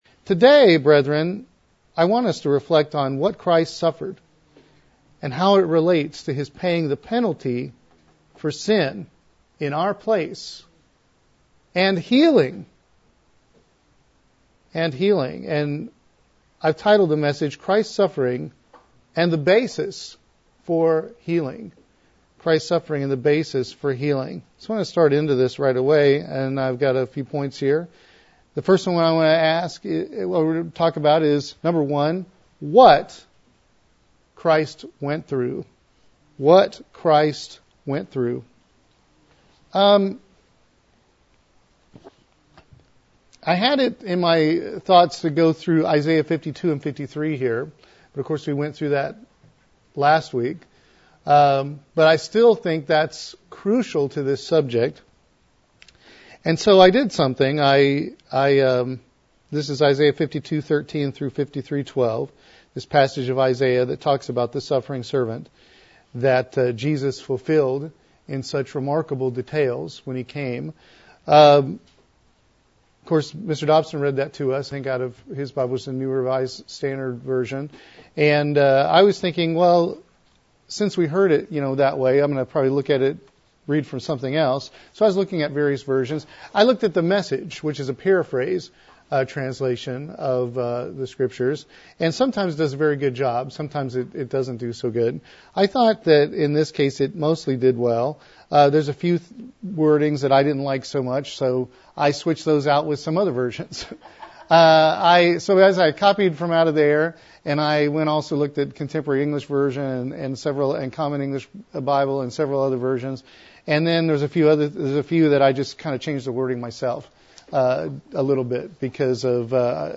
Given in Columbia - Fulton, MO